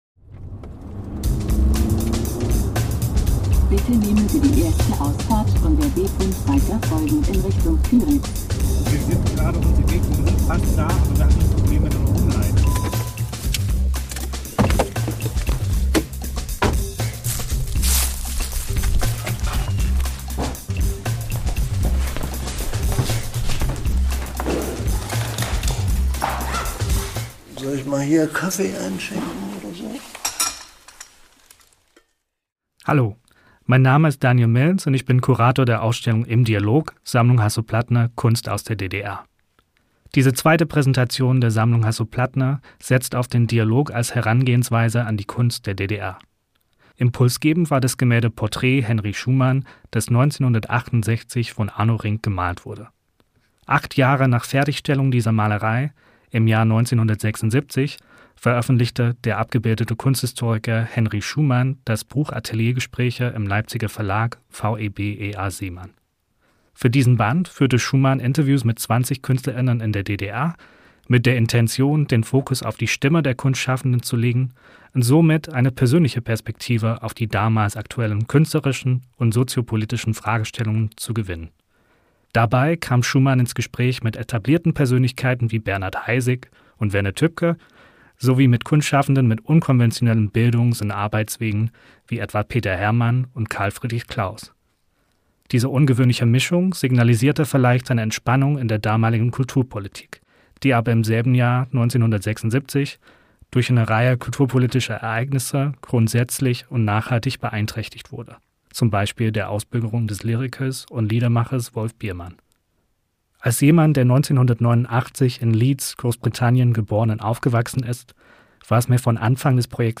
in einem alten Gasthof in Teetz bei Kyritz
Ein Gespräch über künstlerische Verantwortung, mediale Umbrüche und das Ringen um Ausdruck.